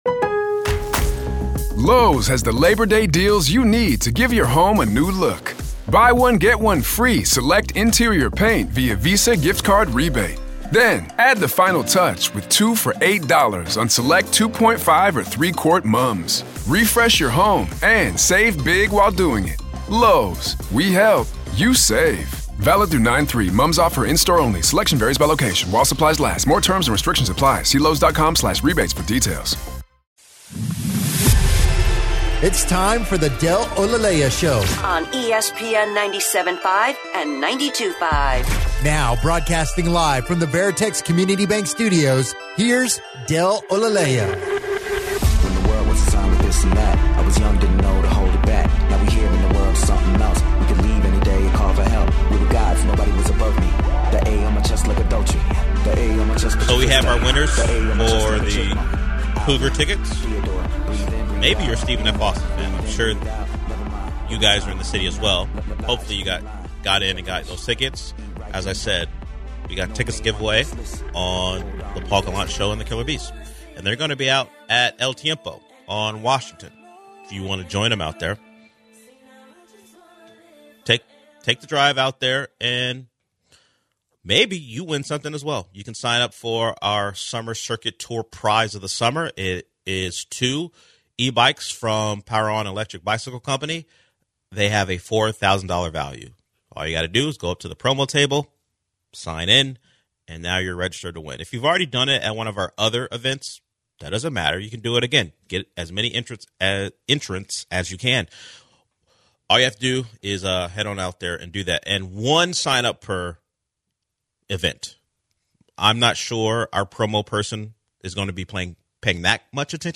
The guys discuss Jerry Jones' podcast appearance and comments on Micah Parsons, are the Cowboys in trouble?